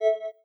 Unlock.ogg